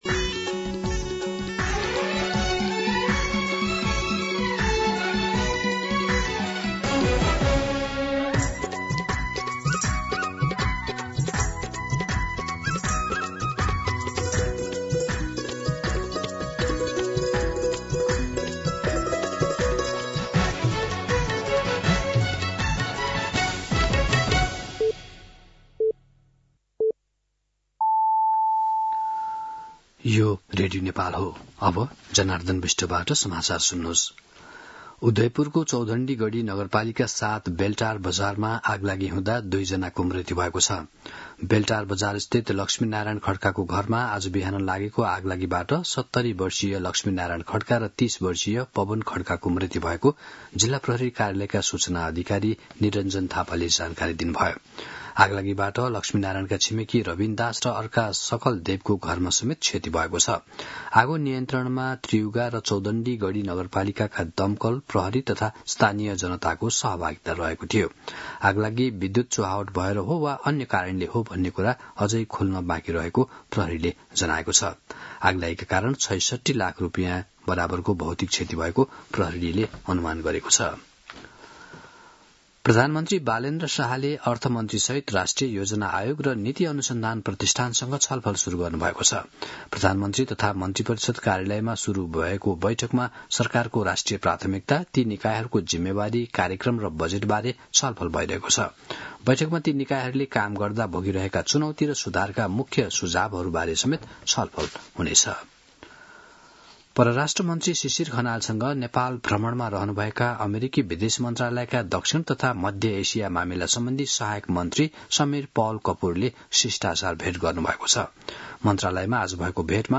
दिउँसो १ बजेको नेपाली समाचार : ८ वैशाख , २०८३
1pm-News-08.mp3